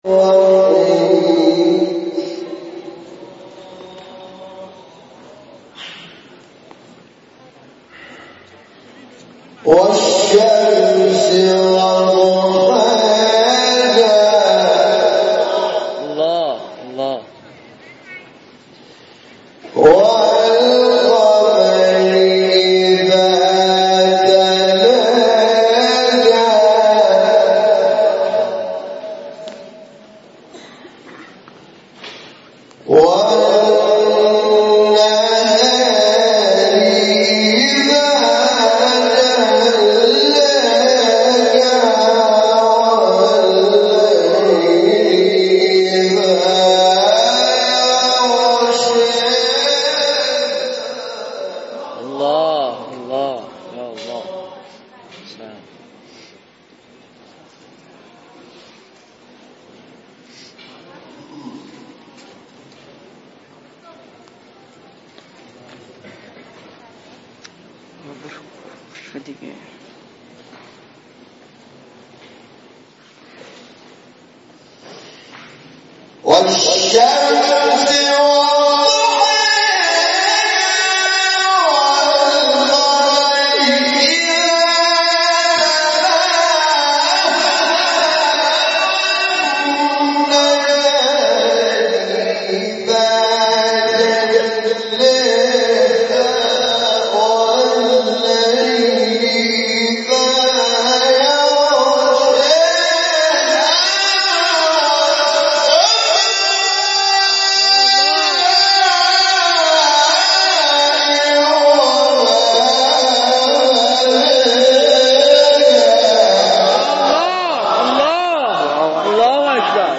تلاوت
مقطعی بسیار زیبا از استاد شاکرنژاد سوره شمس.
مکان تلاوت : تهران